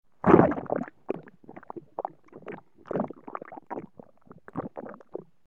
Liquid Glugs; Interior Thick Slosh And Gurgles